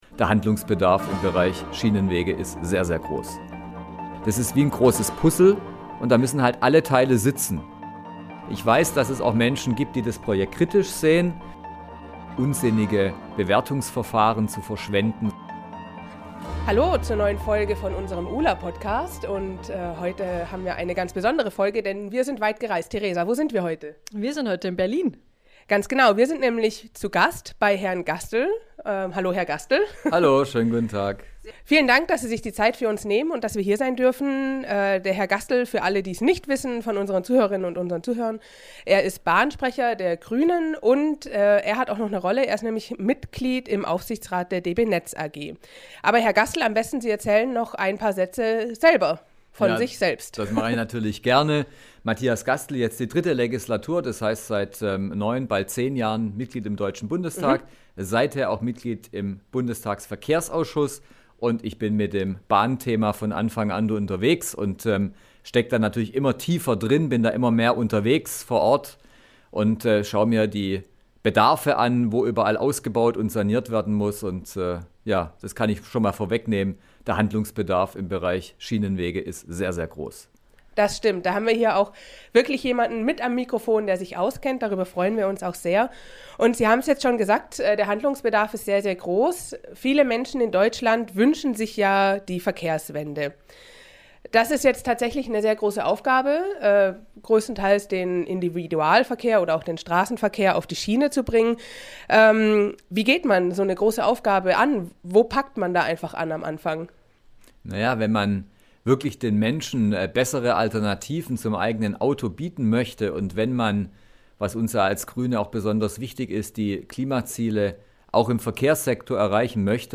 #7 Im Gespräch mit Matthias Gastel ~ Bahnprojekt Ulm-Augsburg: Der Podcast